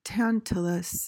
PRONUNCIATION:
(TAN-tuh-luhs)